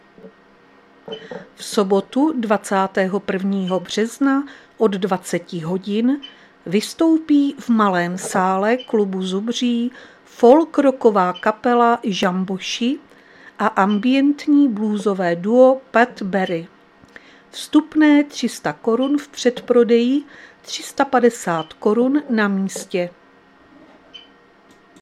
Záznam hlášení místního rozhlasu 19.3.2026
Zařazení: Rozhlas